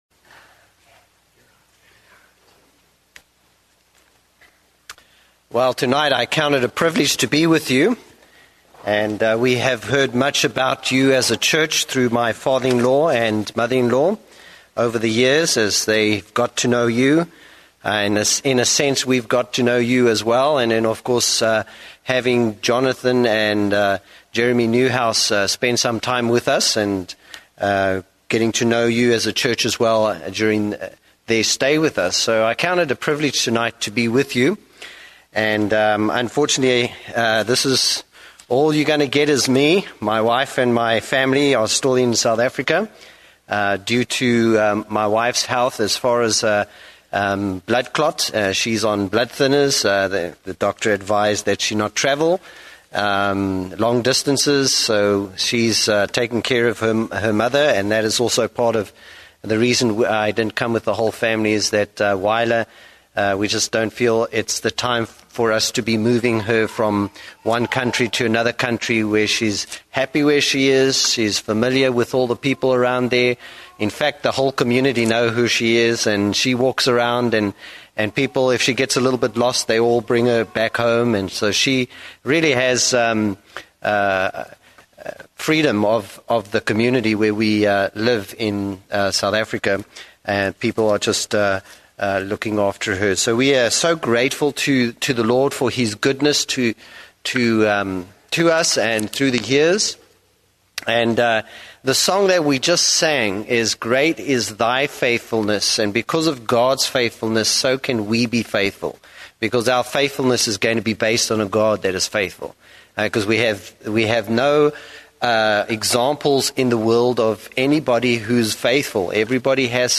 Date: May 10, 2009 (Evening Service)